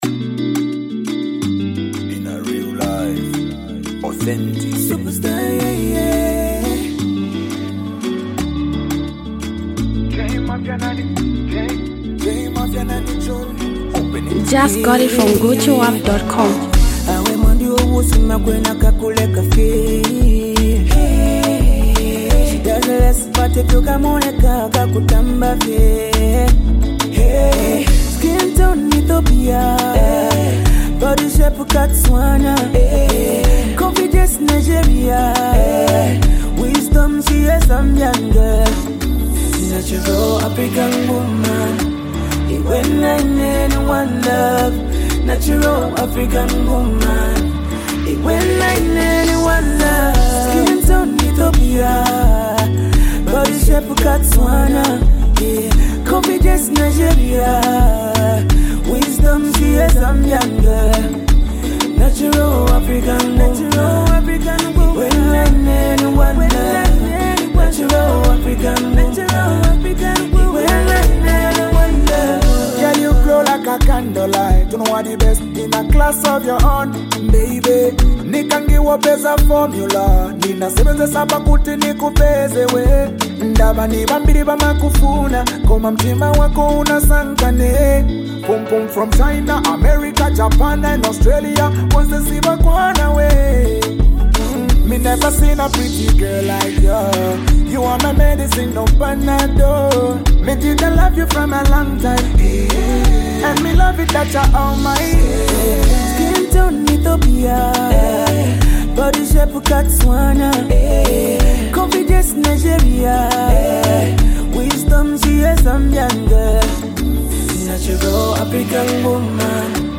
reggae, dancehall, and R&B style
soulful vocals